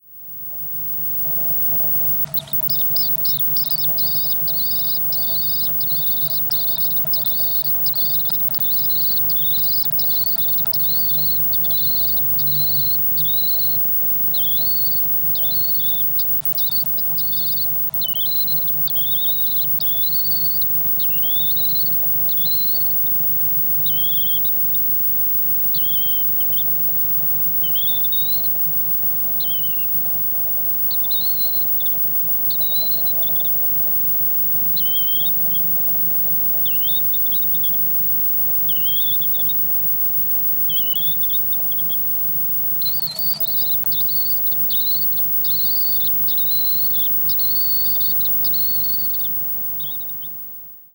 As it will most probably be my last opportunity to do so this year, I've made another recording of sounds in the box tonight at around 10.30pm. Once again it features the quiet, almost wheezy sound made by the remaining chick. At the beginning it is quite rapid as the parent preens the chick's neck. Then, as it relaxes again the sound gradually becomes more rhythmical in time with its breathing before another short preen ups the tempo again near the end.
The recording was made with the input level up high, and it has processed through a high pass filter to remove the constant rumble of distant traffic.